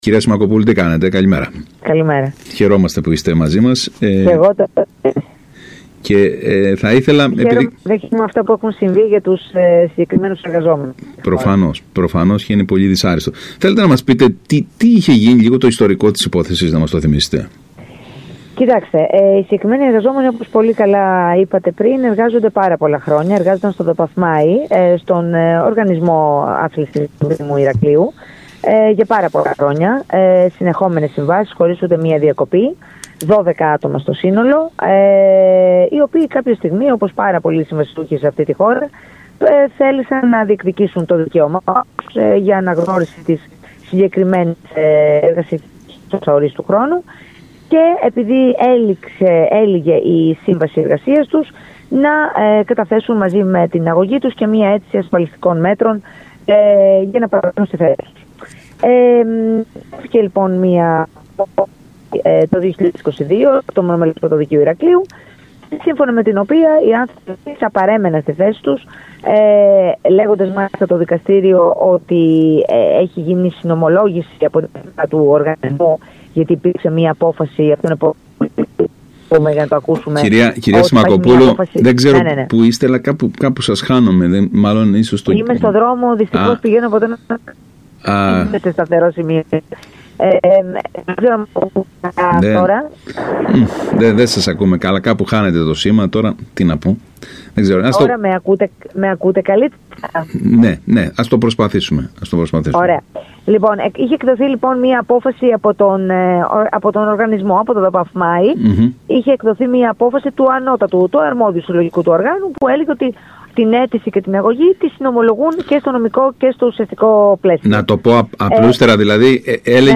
μιλώντας στον ΣΚΑΙ Κρήτης